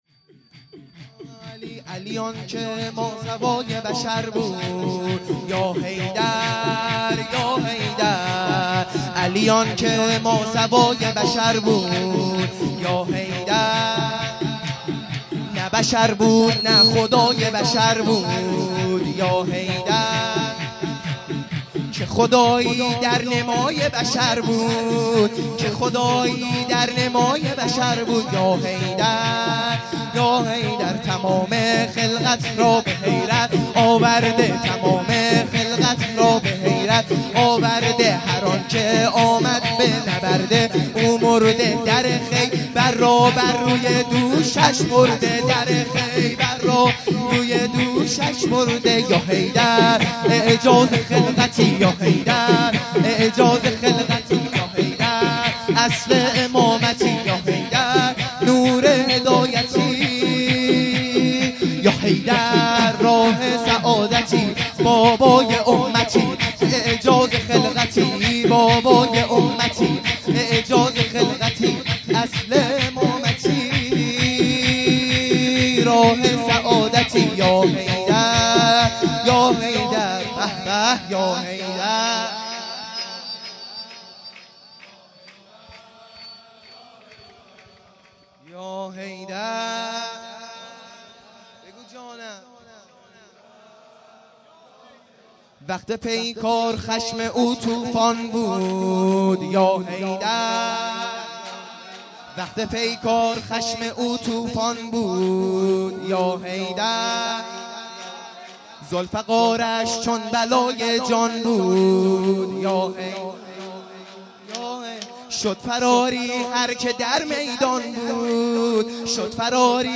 سرود | علی آنکه ماسوای بشر
عیدانه میلاد سرداران کربلا (شب دوم)